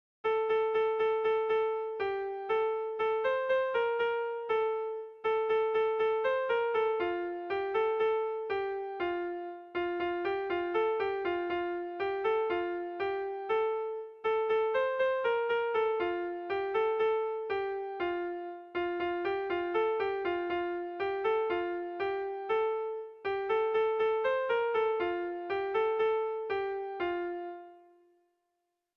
Erlijiozkoa
Hamabiko txikia (hg) / Sei puntuko txikia (ip)
ABDBDB